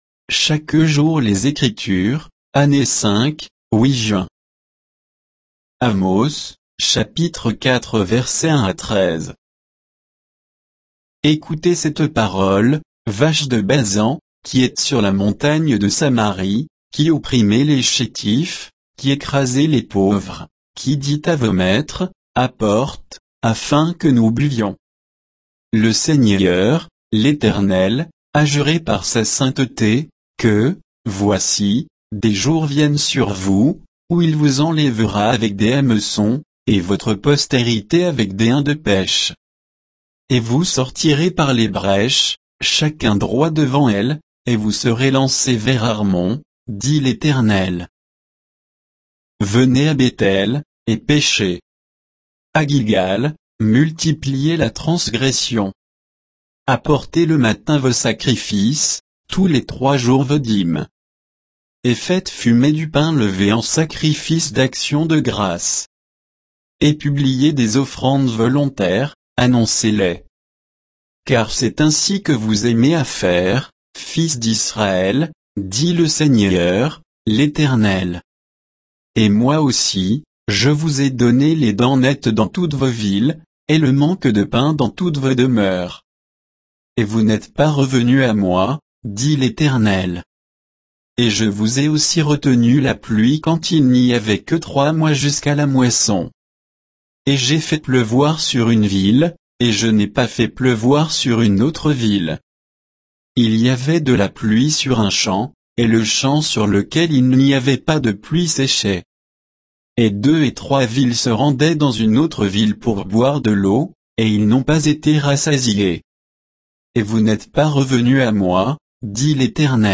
Méditation quoditienne de Chaque jour les Écritures sur Amos 4